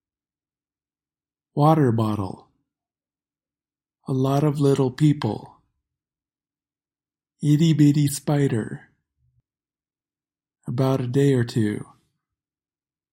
This is called an alveolar tap or flap t.